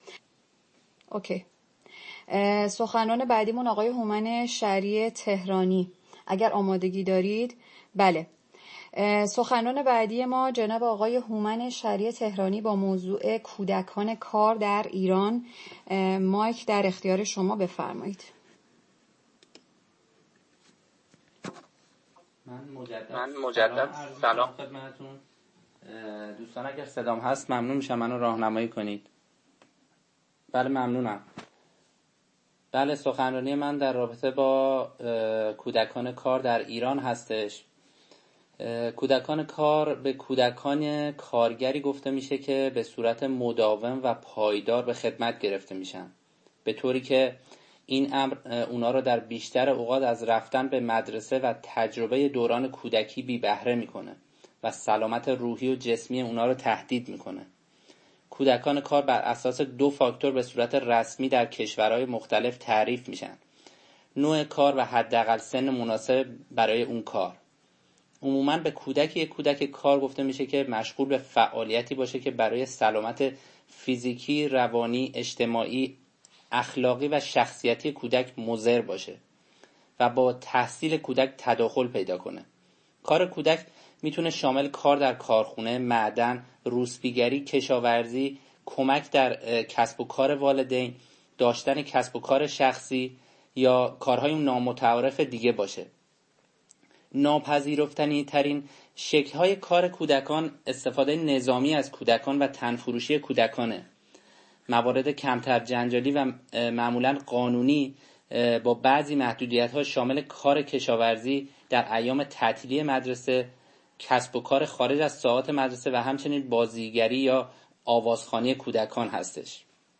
The Giessen Agency Meeting Audio Report-April 26, 2020
The Giessen Agency Meeting was held on April 26, 2020 at 18:00 o’clock to the Central European time, with the participation of Agency members and other Human Rights activists in Palktalk cyberspace…